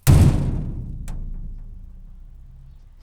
gate02-closed.ogg